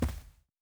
Footstep Carpet Walking 1_09.wav